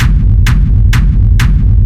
Sub bass Free sound effects and audio clips
• Techno Jumpy Kick Bassy Click.wav
Techno_Jumpy_Kick_Bassy_Click__HNe.wav